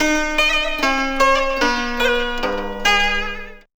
CHINAZITH2-R.wav